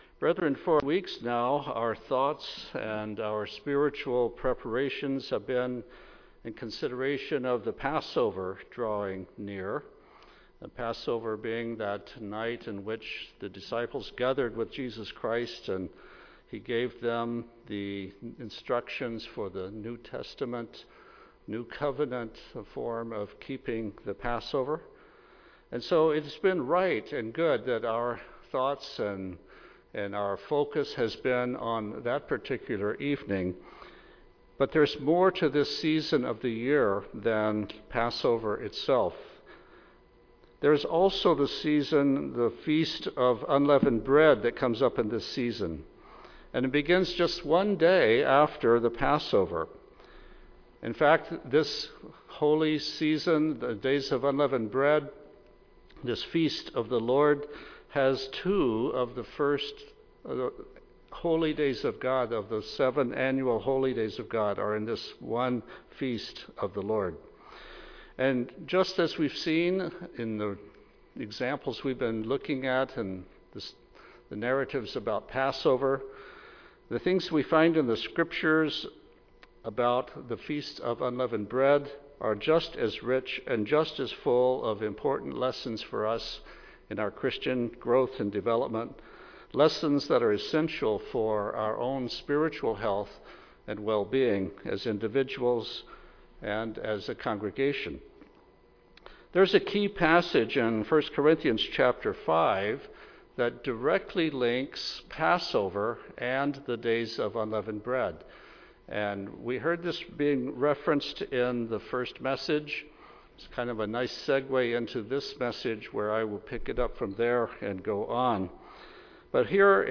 Sermons
Given in Tacoma, WA